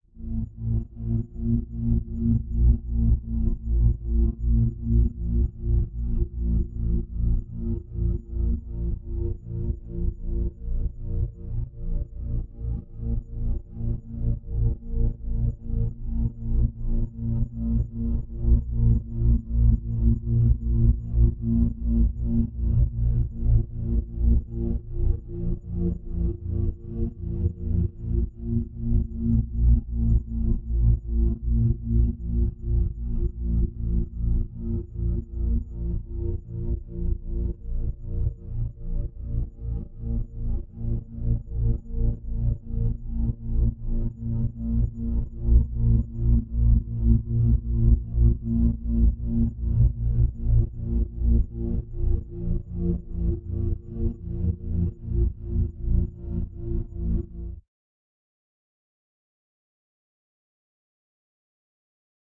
描述：使用颗粒合成（我自己的实现）对尺八样本进行了大量处理
Tag: 粒状 尺八 合成